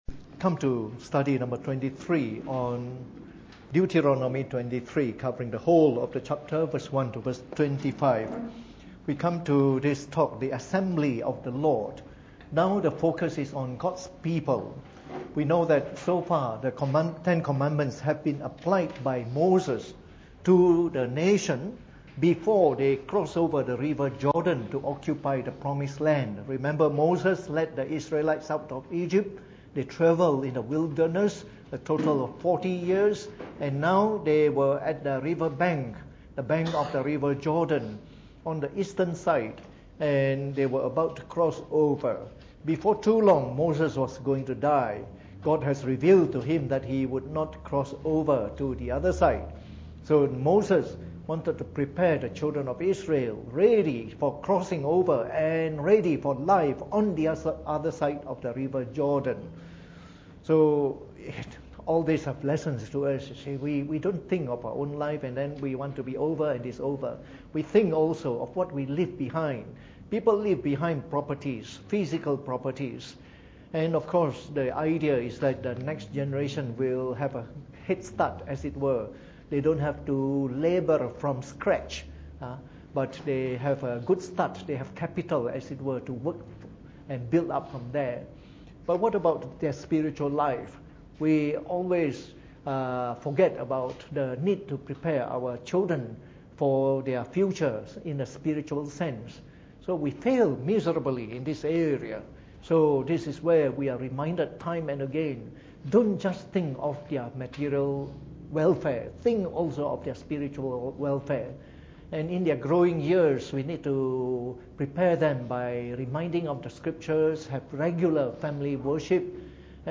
Preached on the 18th of July 2018 during the Bible Study, from our series on the book of Deuteronomy.